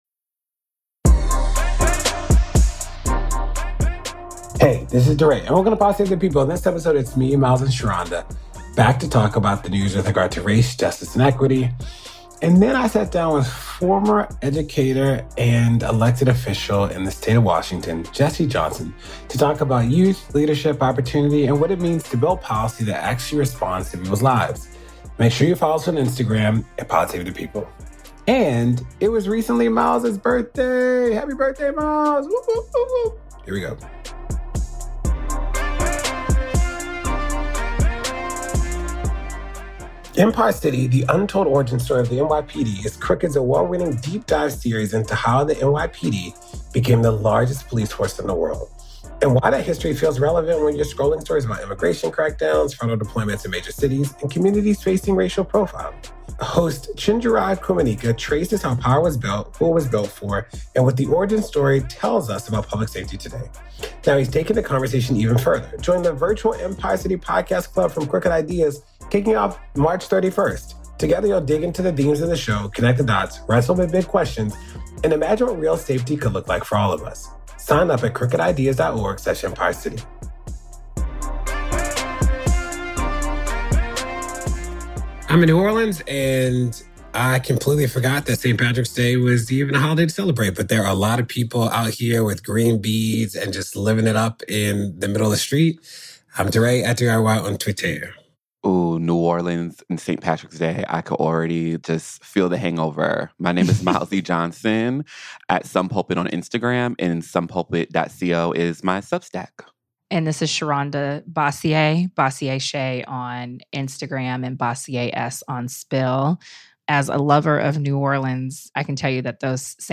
DeRay interviews former Washington State Representative for the 30th Legislative District Jesse Johnson.